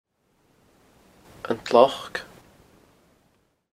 The locals pronounced it: un tlock.